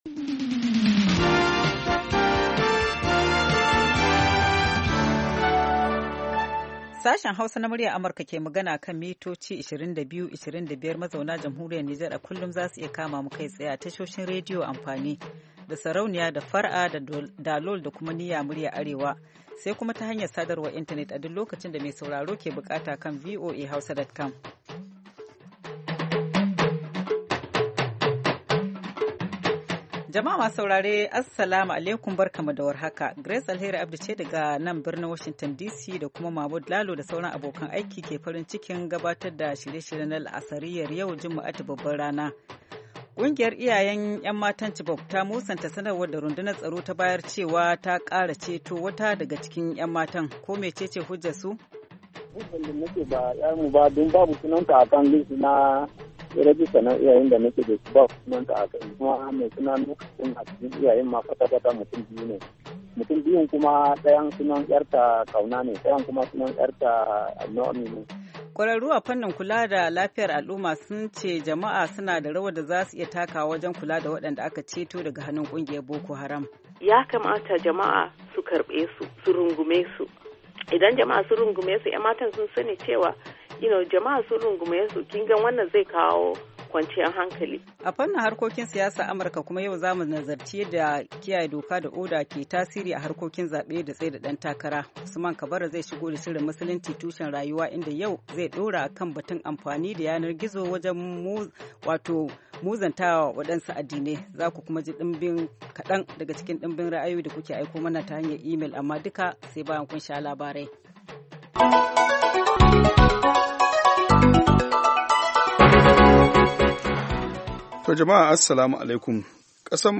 Da karfe 4 na yamma agogon Najeriya da Nijar zaku iya jin rahotanni da labarai da dumi-duminsu daga kowace kusurwa ta duniya, musamman ma dai muhimman abubuwan da suka faru, ko suke faruwa a kusa da ku a wannan rana.